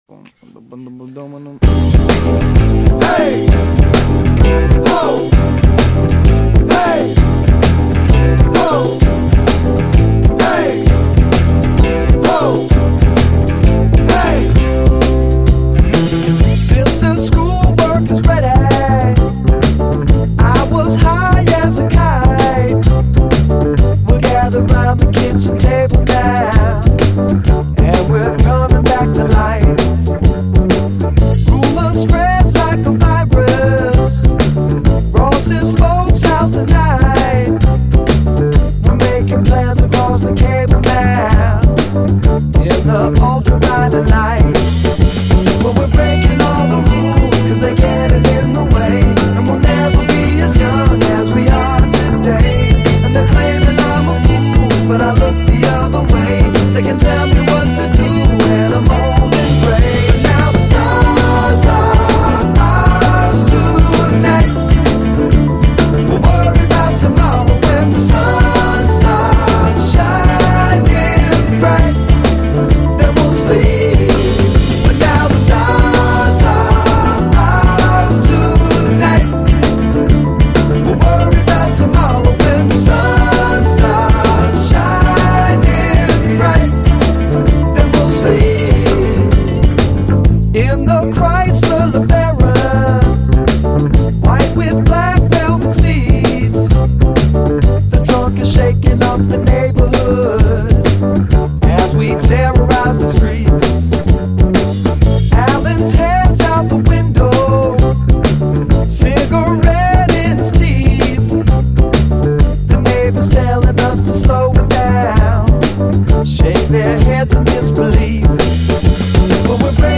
funk-soul
sprinkles more 70’s sounds into the stew of retro-soul